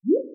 SFX_ItemPickUp_03_Reverb.wav